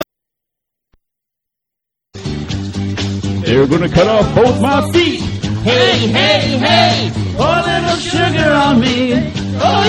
Rock and Roll classics